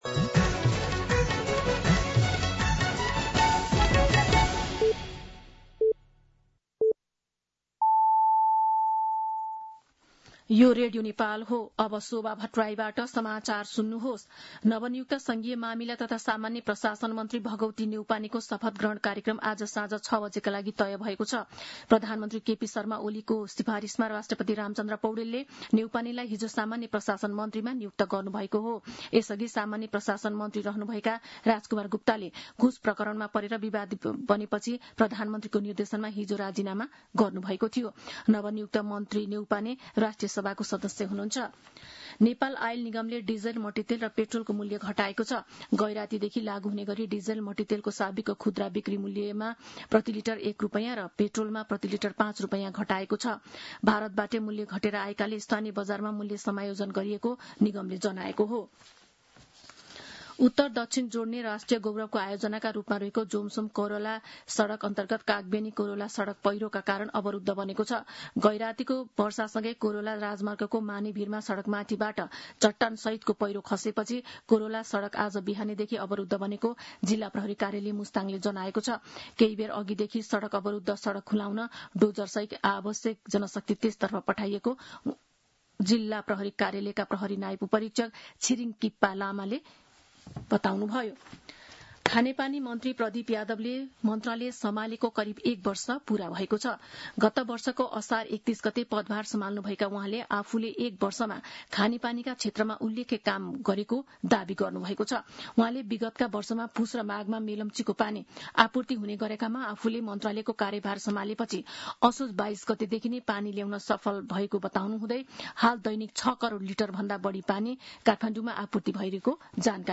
मध्यान्ह १२ बजेको नेपाली समाचार : १८ पुष , २०२६